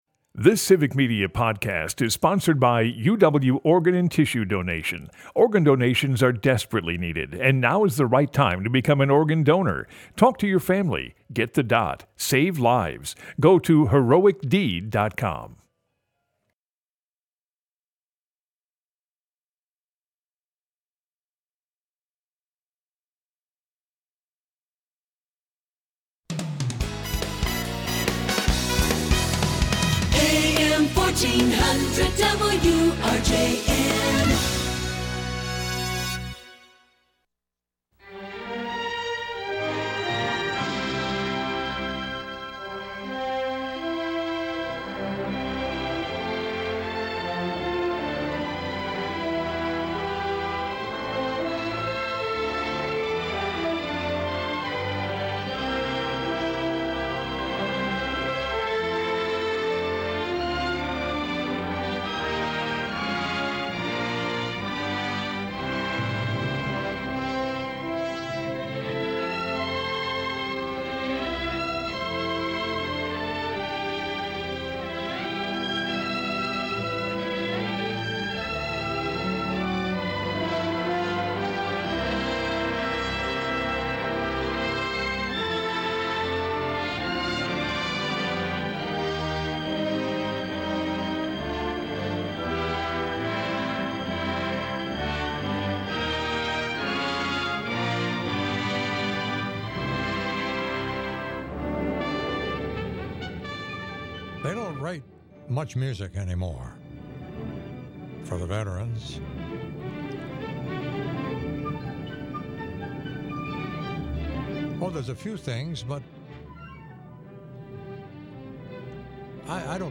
an infinitely eclectic show
Broadcasts live 7 a.m. to noon Sunday mornings across Wisconsin.